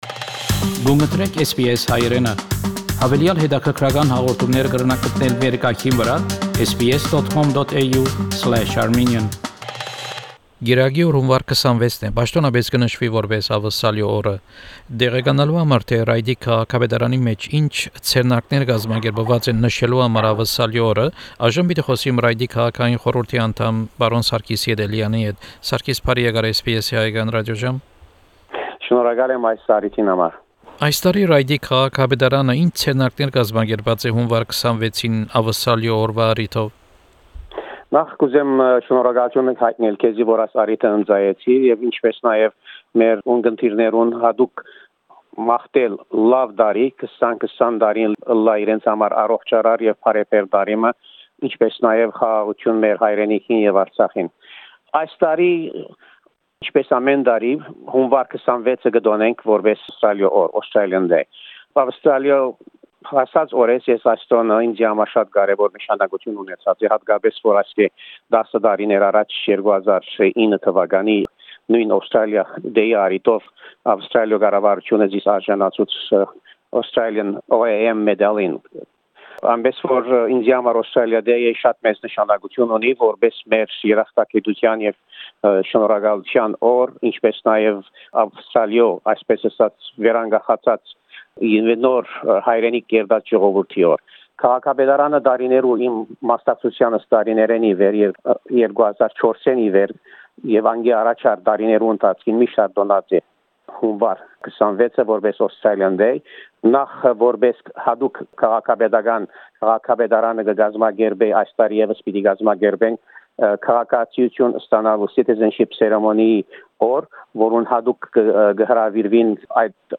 Հարցազրոյց Ռայտի քաղաքապետական խորհուրդի անդամ Պրն Սարգիս Ետելեանի հետ: Հարցազրոյցի նիւթն է Աւստրալիոյ Օրուայ կարեւորութիւնը և Ռայտ քաղաքապետարանի կազմակերպած ձեռնարկները Յունուար 26ին Աւստրալիոյ օրուայ առիթով: